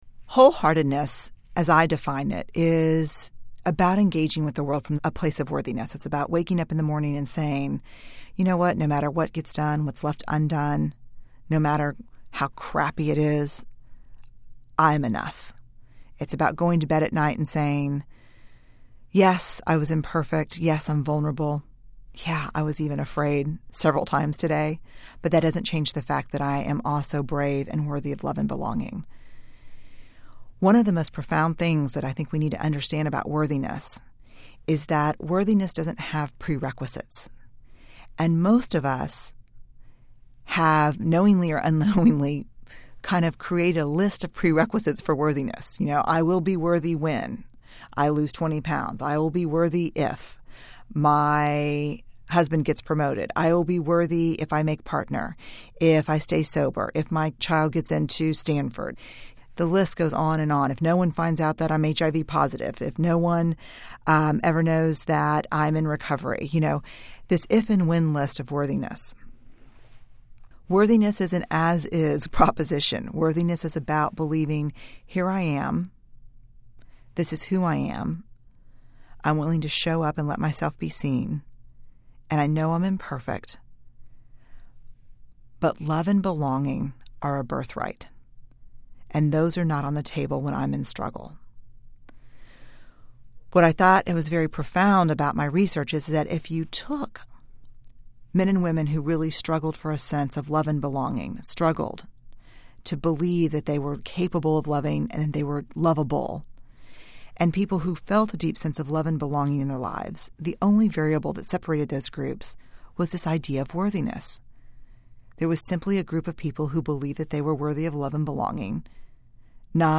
Audio Books